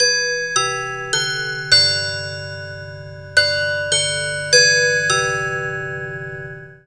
ClockChimes.wav